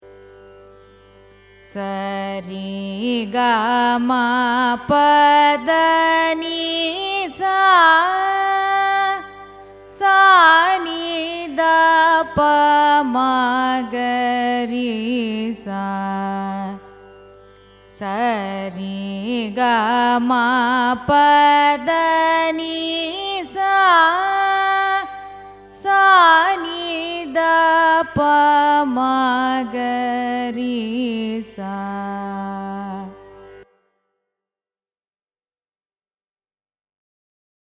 28th mela Alias: harikambhoji, hari-kambhodi, hari-kambodhi, harikambhodi, harikambodhi,
raga-hari_kambhoji-arohanam_avarohanam.mp3